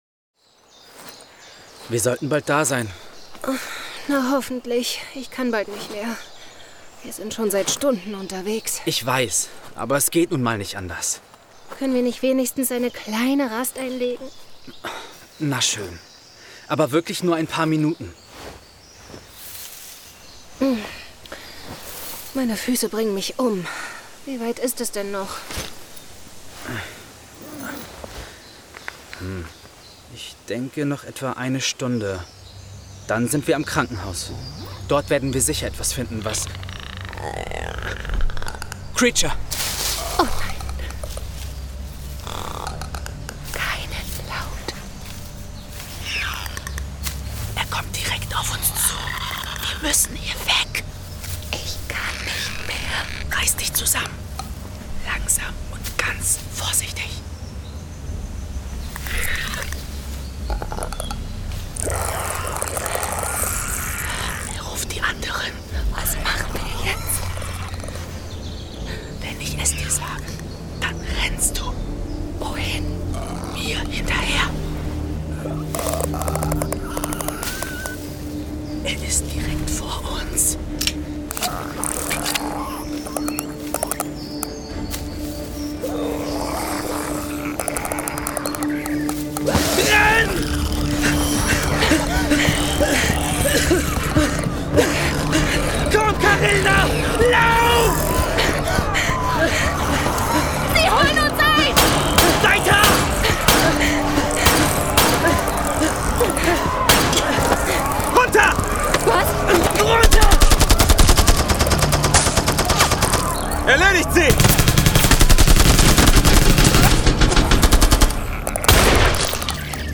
Zwei Freunde auf der Flucht in einer kalten, postapokalyptischen Welt. Hörspiel, Horror
Düster